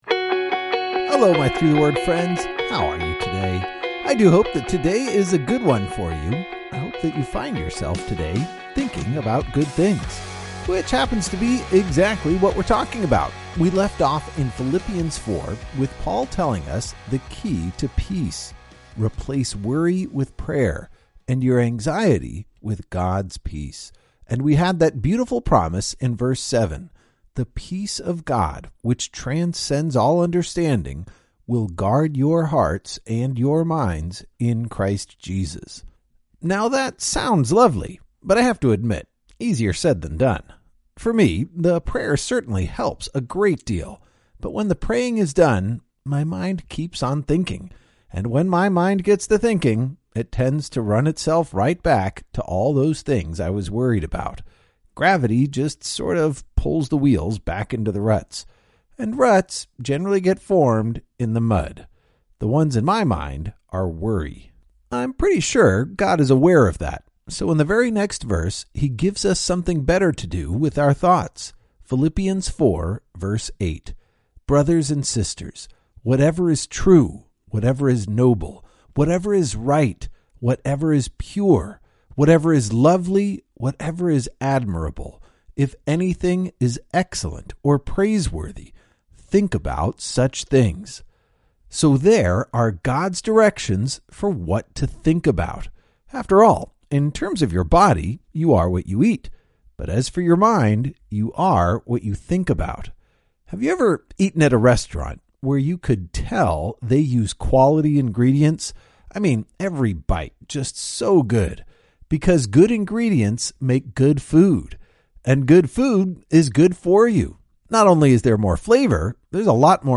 This devotional works best as an audio experience.